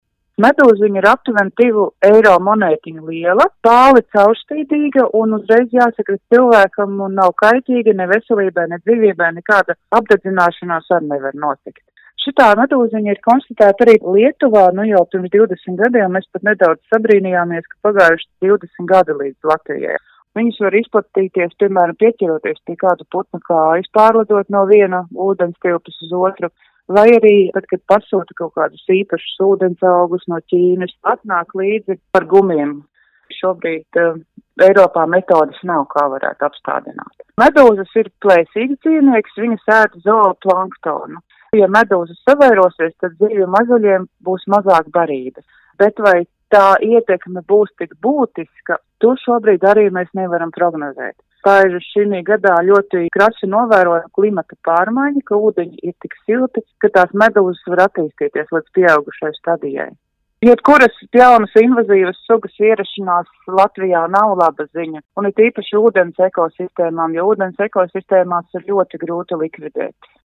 RADIO SKONTO Ziņās par jaunu klimata pārmaiņu radītu pārsteigumu